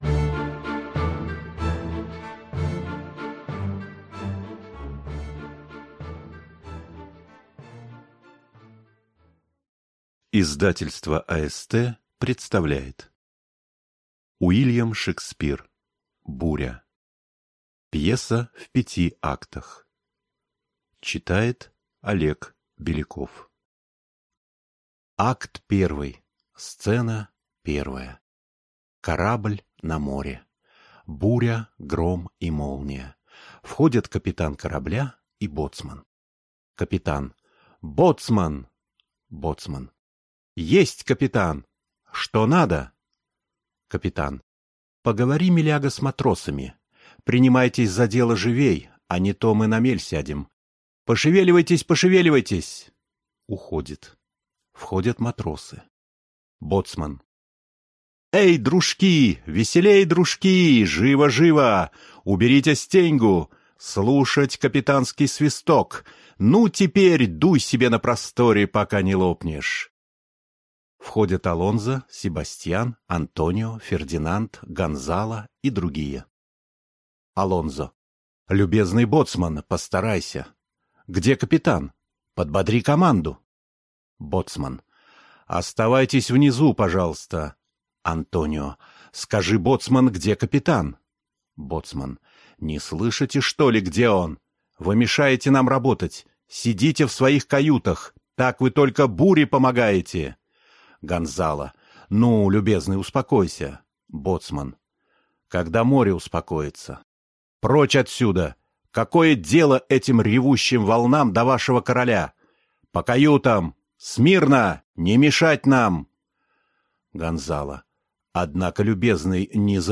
Аудиокнига Буря | Библиотека аудиокниг